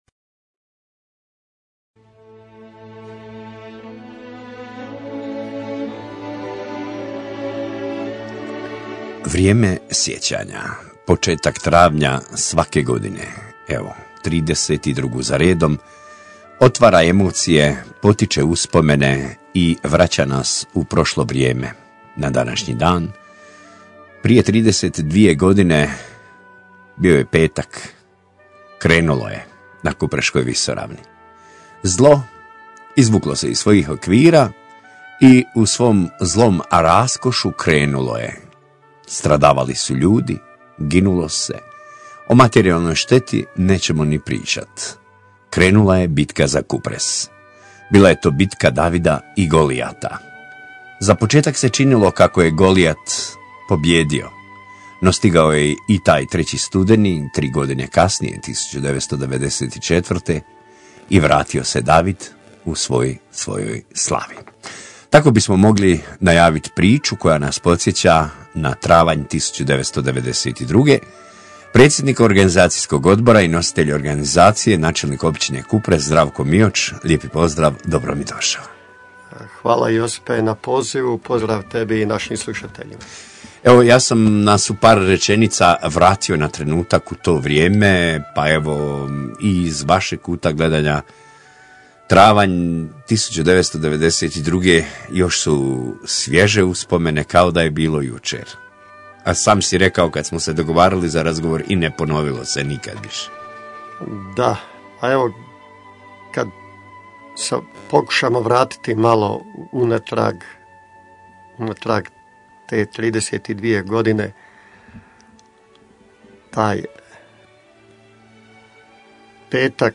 32. obljetnica u spomen žrtvi hrvatskog naroda na Kupresu razgovor s načelnikom općine Kupres